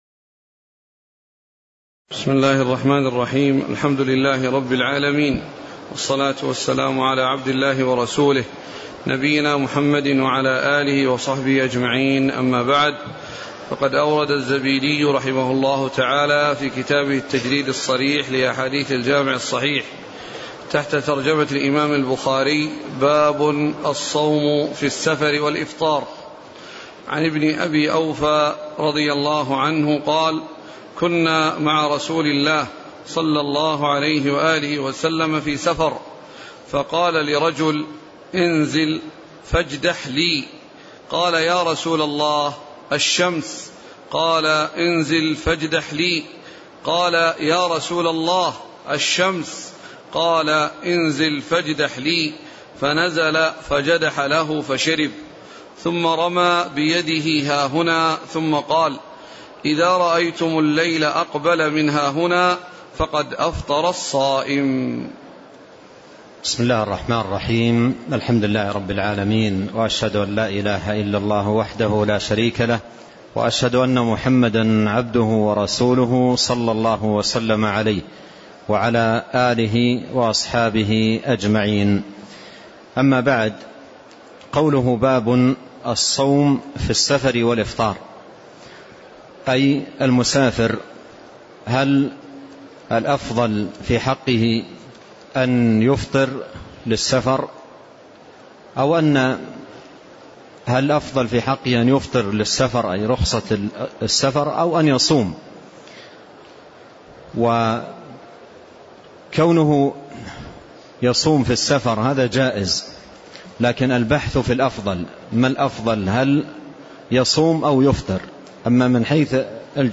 تاريخ النشر ٧ رمضان ١٤٣٤ هـ المكان: المسجد النبوي الشيخ